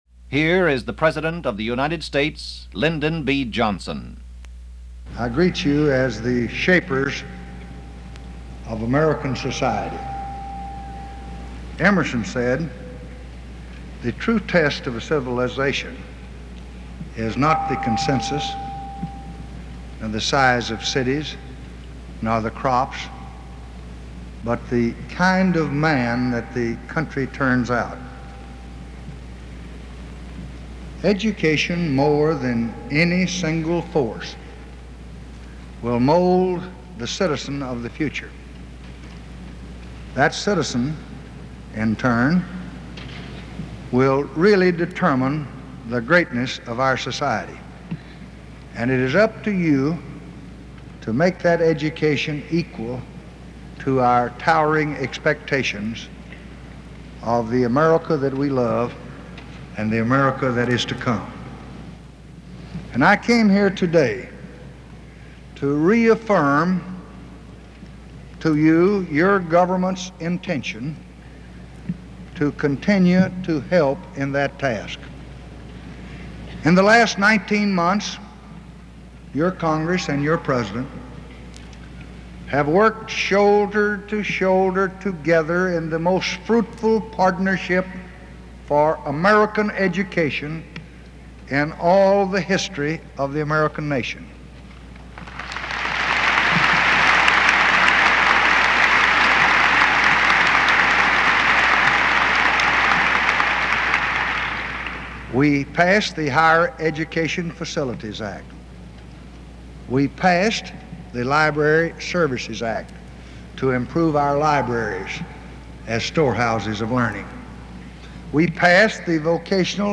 Lyndon Johnson Addresses The National Education Association - July 2, 1965